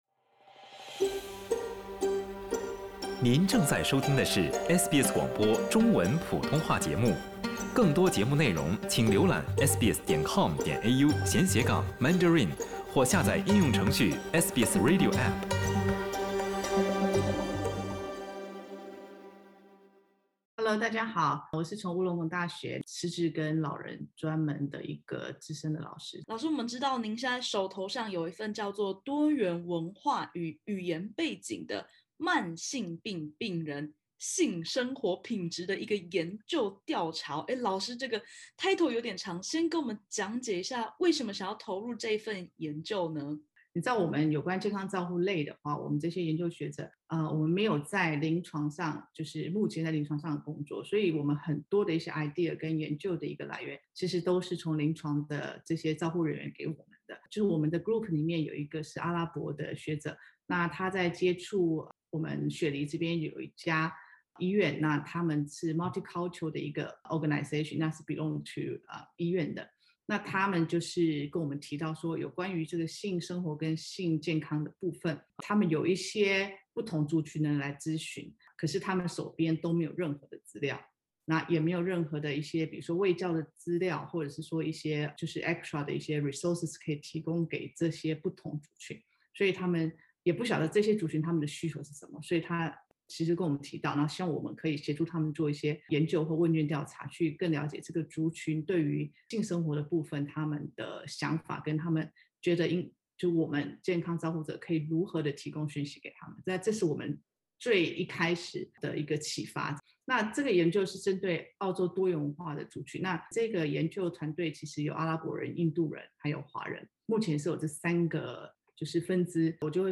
談論性事令您害羞？伍倫貢大學團隊正在廣泛募集華人慢性病病患，對性生活品質和性健康知識的問卷調查。（點擊首圖收聽採訪podcast）